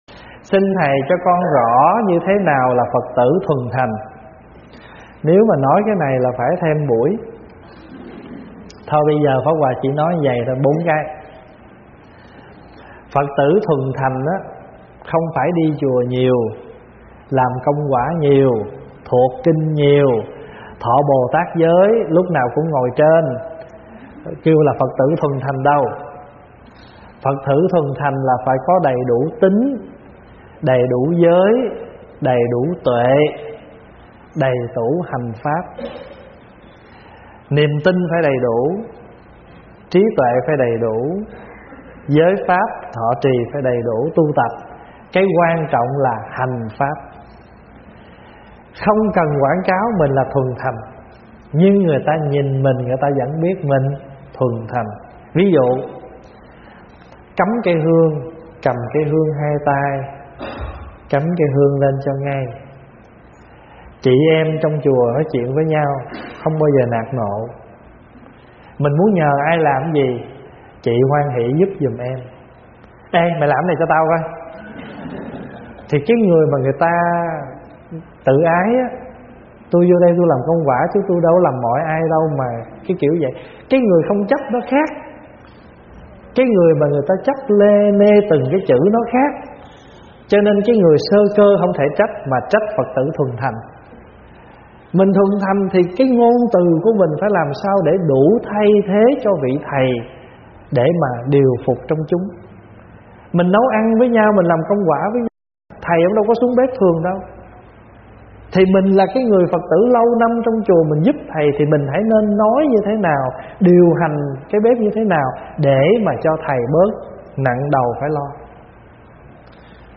Mời quý phật tử nghe mp3 vấn đáp THẾ NÀO LÀ PHẬT TỬ THUẦN THÀNH? - ĐĐ. Thích Pháp Hòa giảng
Mp3 Thuyết Pháp     Thuyết Pháp Thích Pháp Hòa     Vấn đáp Phật Pháp